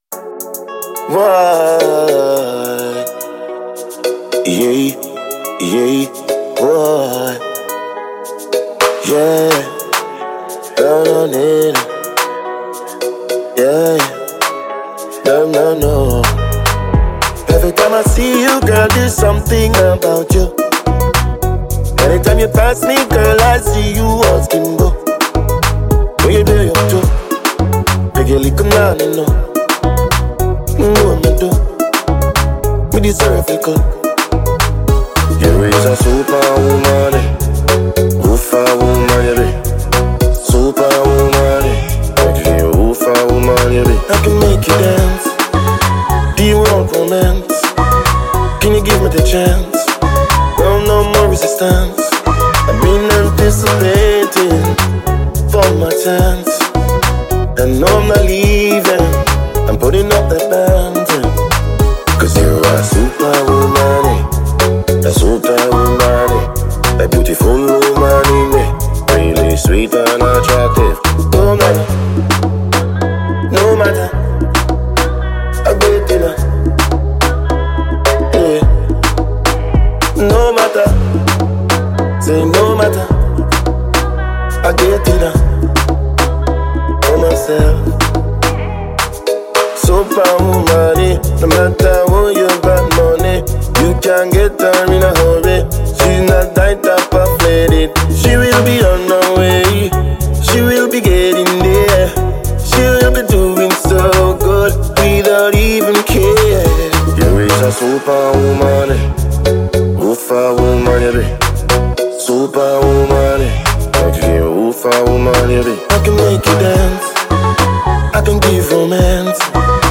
Award-winning Jamaican dancehall musician